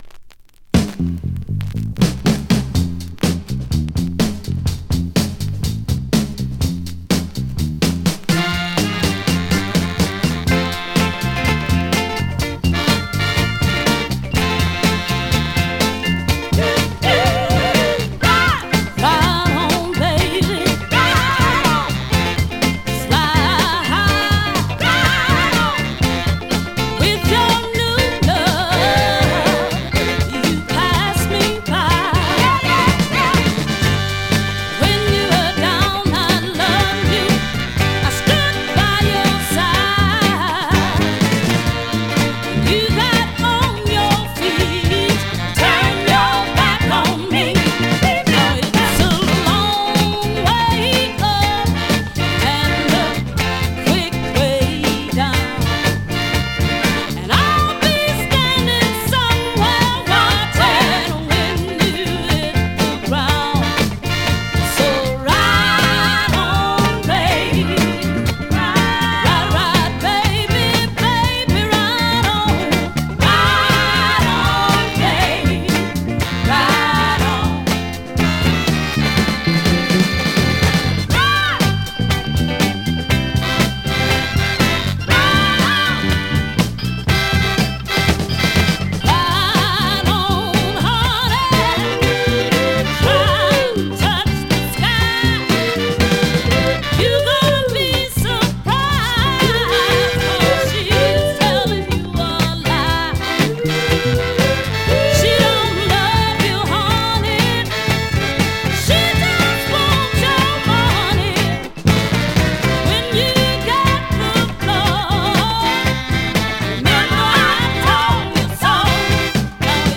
Vinyl very clean plays great .
R&B, MOD, POPCORN , SOUL